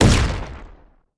etfx_explosion_rocket2.wav